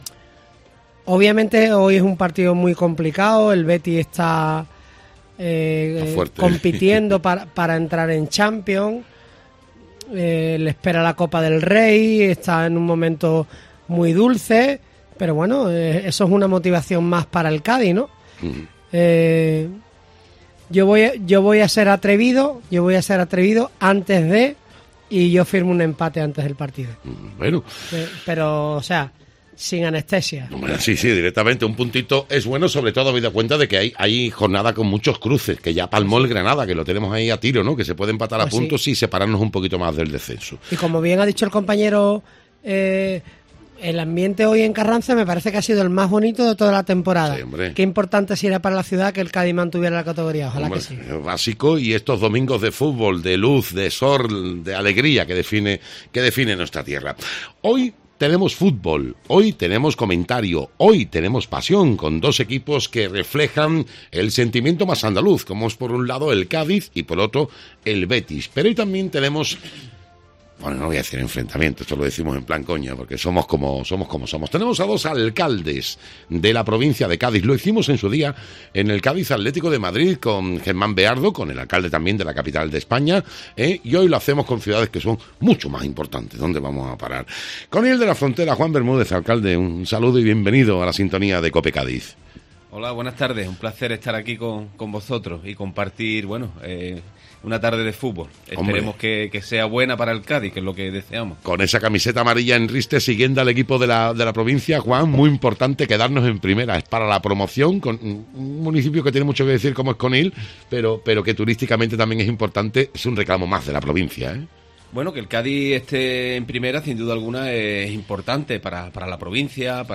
Escucha el resumen sonoro del partido del Cádiz ante el Betis con los comentarios de dos alcaldes de la provincia de Cádiz
El resumen sonoro del Cádiz 1-2 Betis